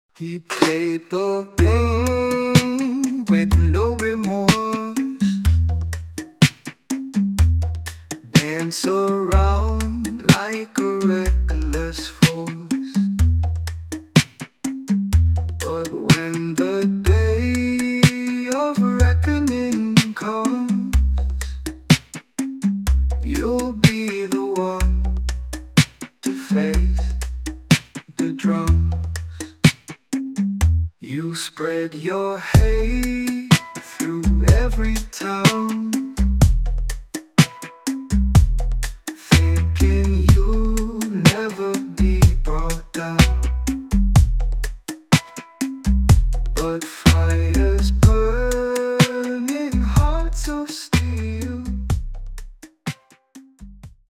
Short version of the song, full version after purchase.
An incredible R & B song, creative and inspiring.